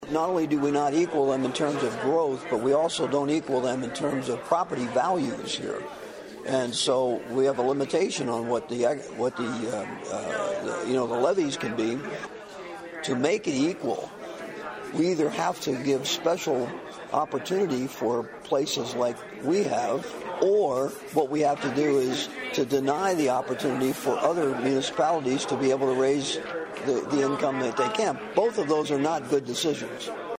STATE REPRESENTATIVE BOB HENDERSON WAS AMONG THE LAWMAKERS LISTENING TO THE CITY’S CONCERNS.